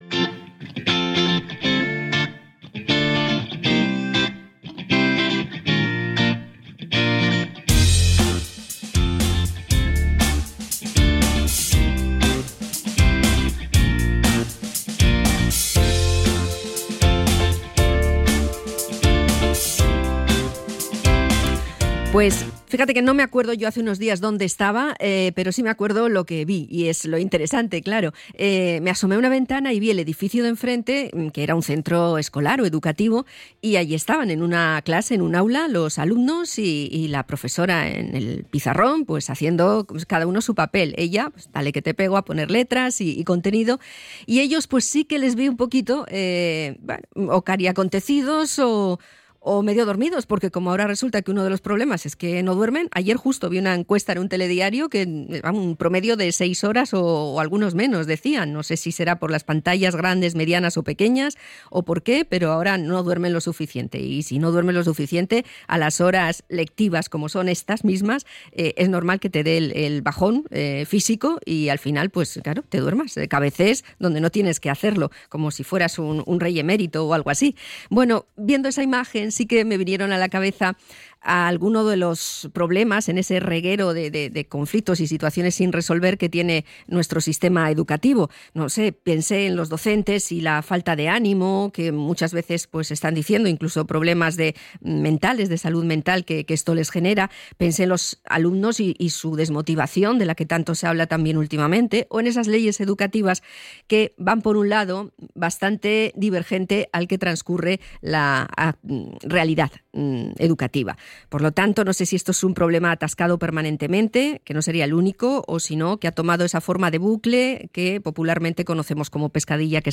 INT.-INCOMPETENCIAS-BASICAS.mp3